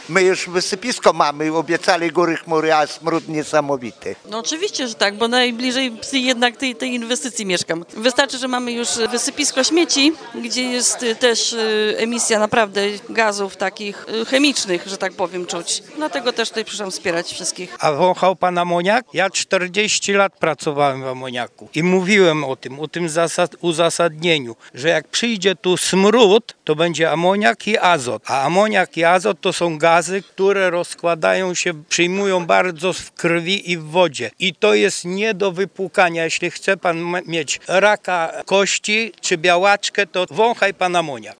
W niedzielę (11.02) mieszkańcy miejscowości spotkali się z wójtem gminy Pozezdrze.
– W okolicy Pieczarek jest już wysypisko śmieci, teraz może powstać kolejny uciążliwy obiekt – żalili się podczas spotkania.